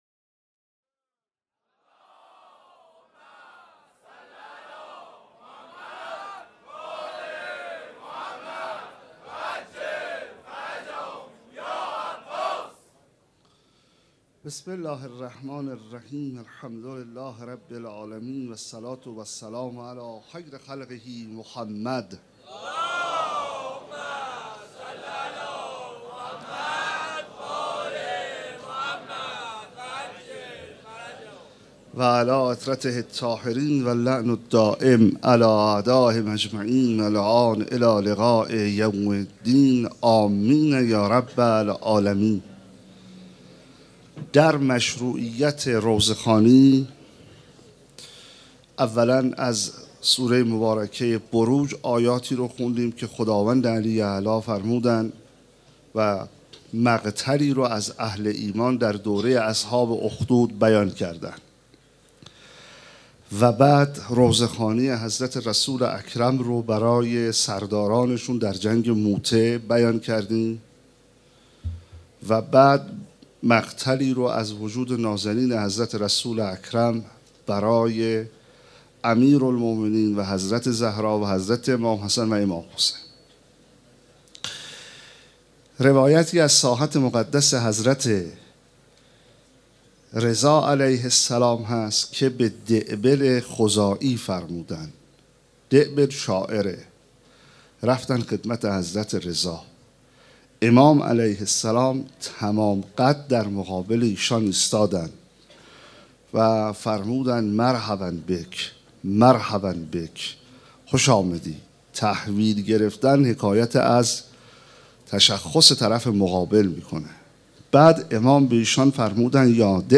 شب چهارم محرم 1436 - هیات رایه العباس B > سخنرانی